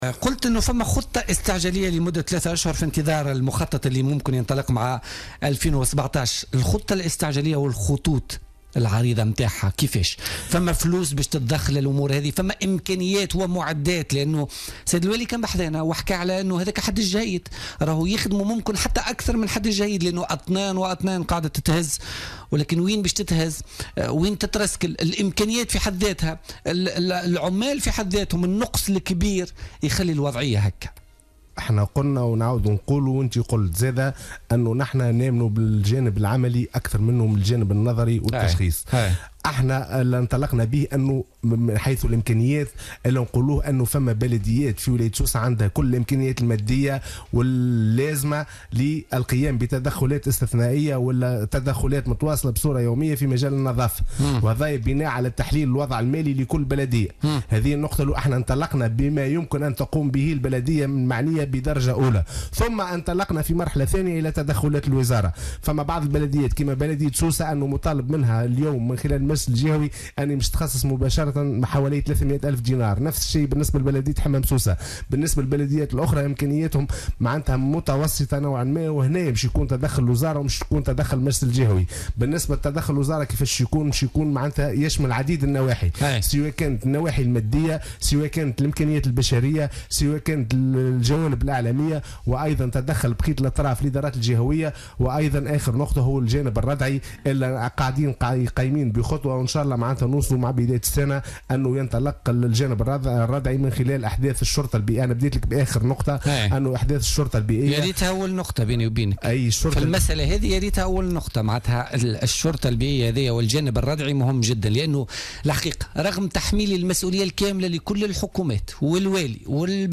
كاتب الدولة لدى وزارة الشؤون المحلية والبيئة يتحدث للجوهرة أف أم